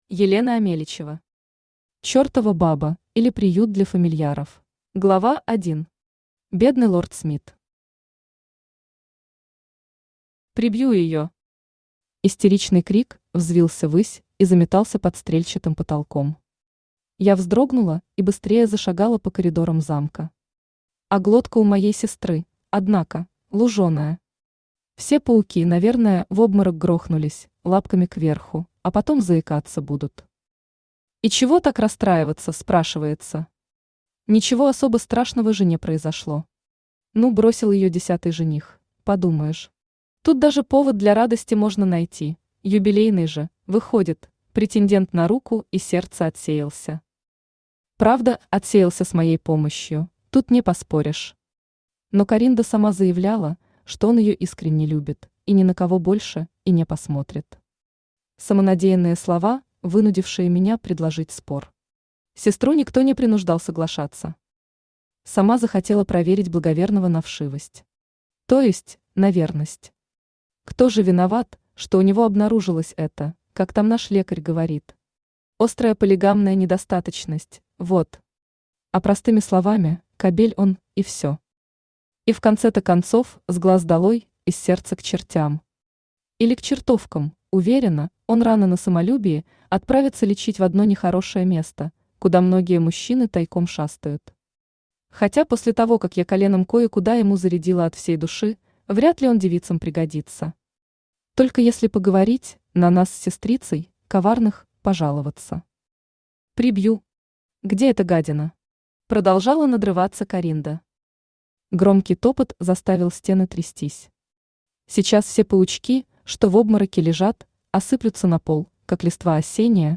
Аудиокнига Чертова баба, или Приют для фамильяров | Библиотека аудиокниг
Aудиокнига Чертова баба, или Приют для фамильяров Автор Елена Амеличева Читает аудиокнигу Авточтец ЛитРес.